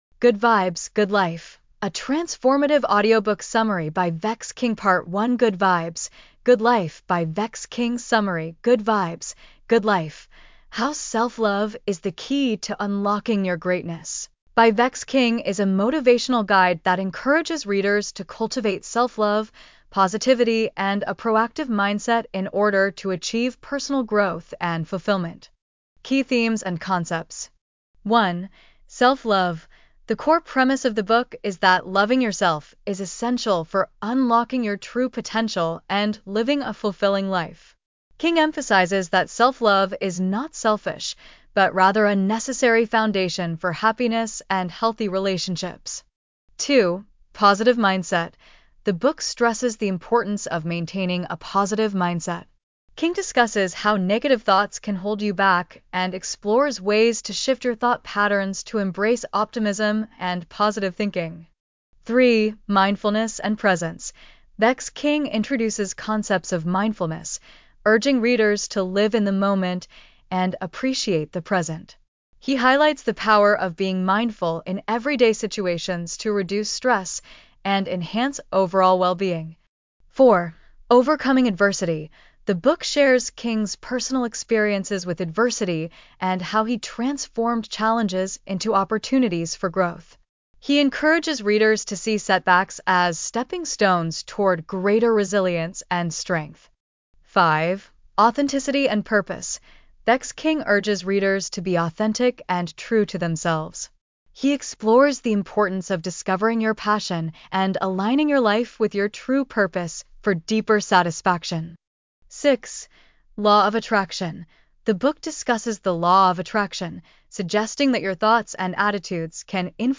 Good Vibes, Good Life: A Transformative Audiobook Summary by Vex King